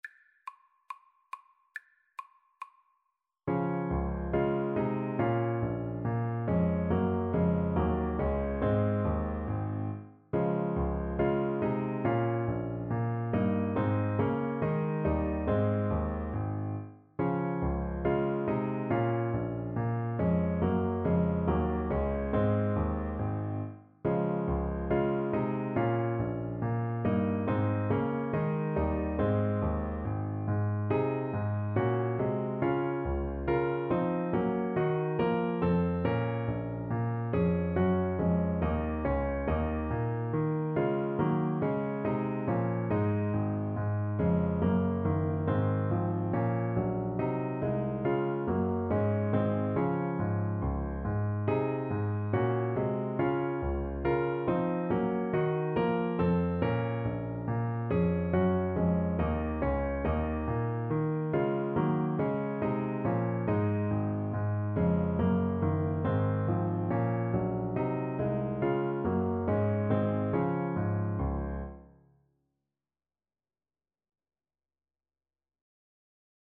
handel_gavotte_hwv491_TPT_kar1.mp3